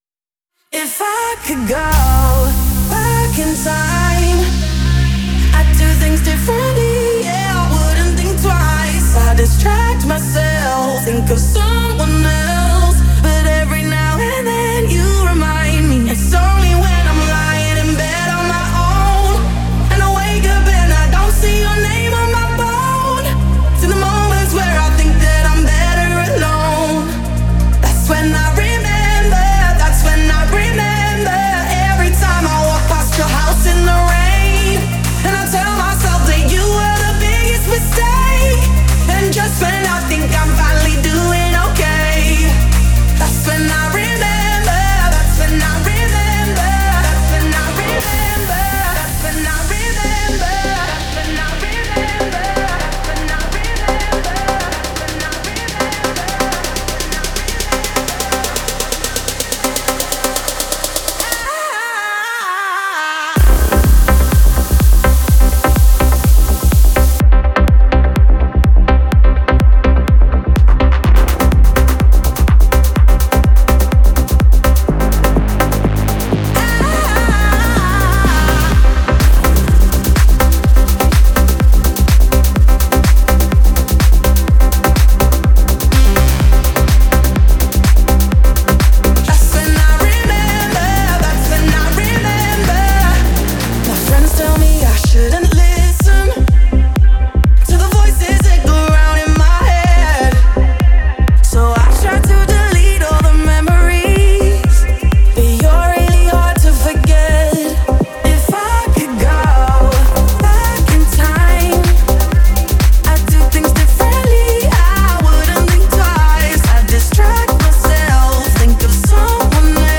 мощный и эмоциональный